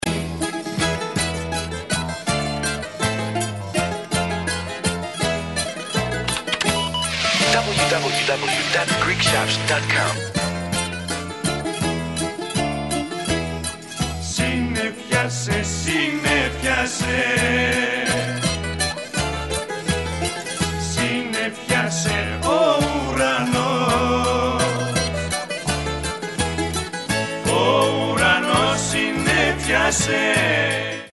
non-stop Greek folk dance songs